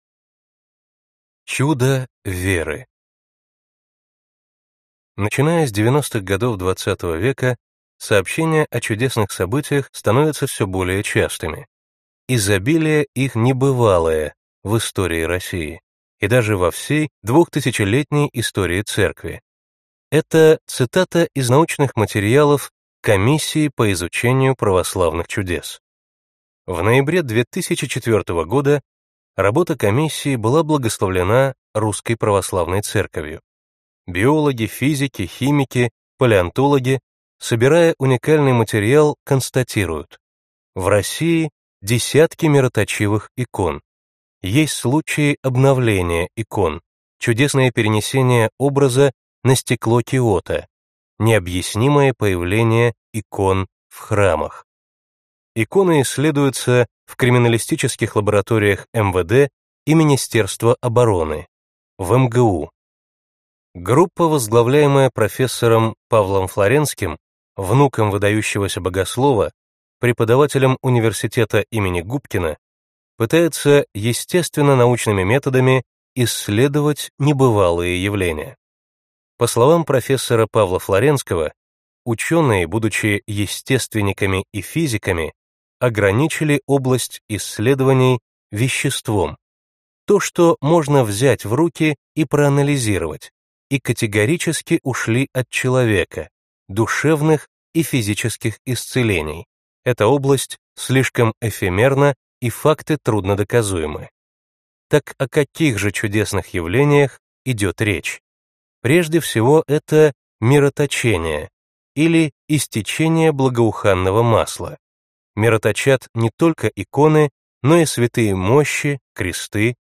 Аудиокнига Чудо веры | Библиотека аудиокниг